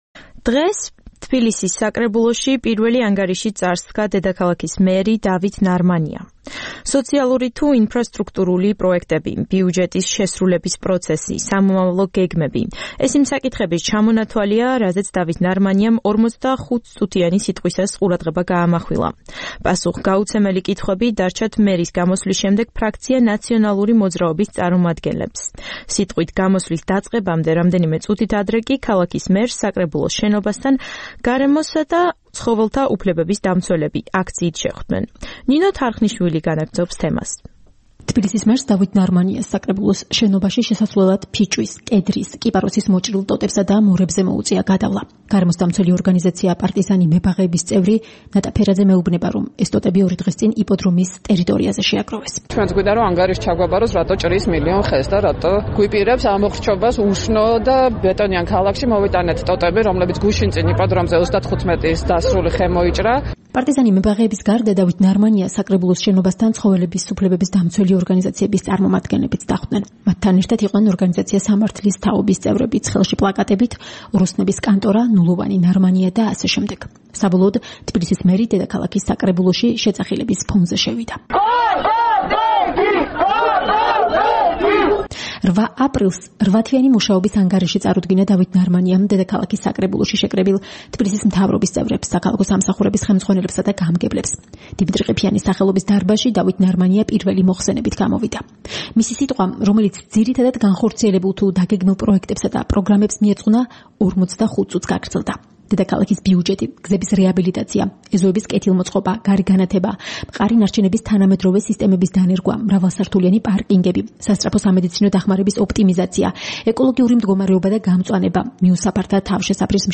მერის პირველი სიტყვა დედაქალაქის საკრებულოში